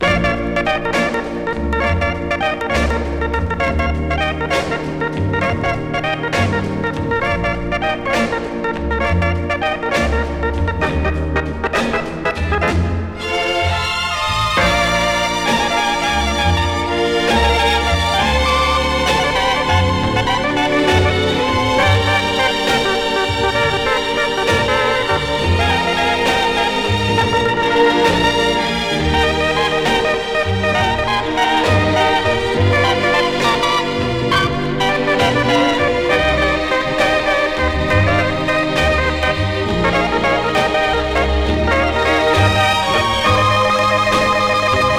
曲ごとの楽器選択が楽しく、演出も有り色取り取りのサウンドで新鮮さを感じます。
Jazz, Easy Listening, Mood　USA　12inchレコード　33rpm　Mono